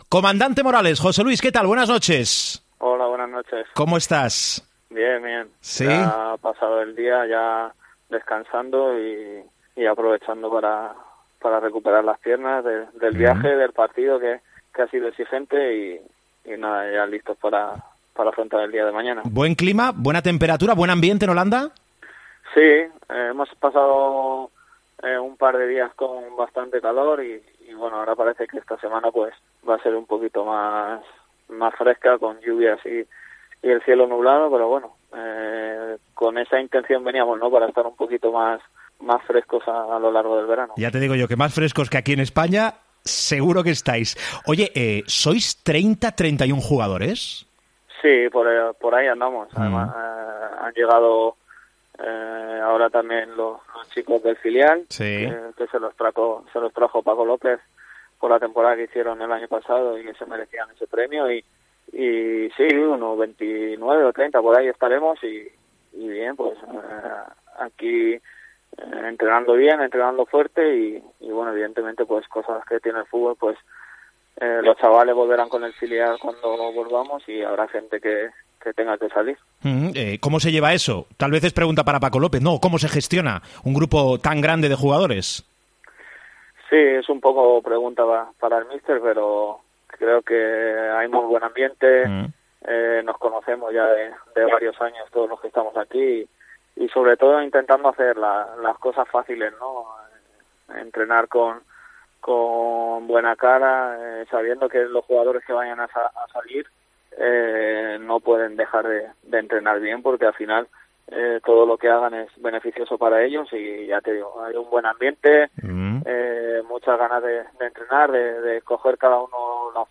El mediapunta del Levante compartió sus impresiones de la pretemporada.